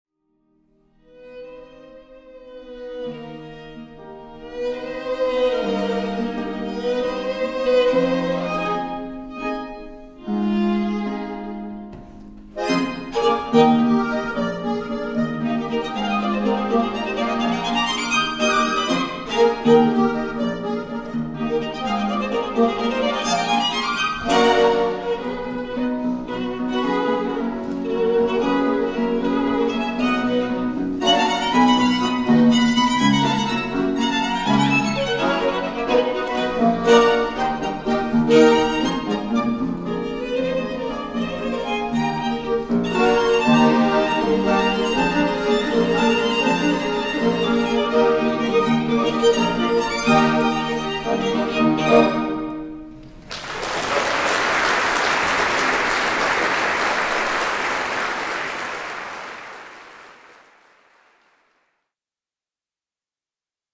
** Quartett mit Knopfharmonika
Aufgenommen live am 13.5.2007,